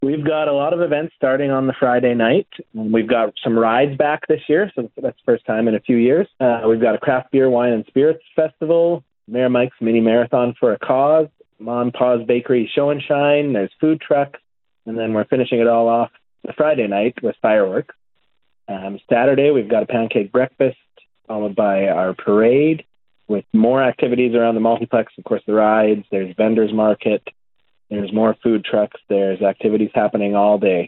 Penhold Mayor Mike Yargeau spoke with CFWE about what attendees can expect when they head down to Penhold’s Fall Festival Celebration.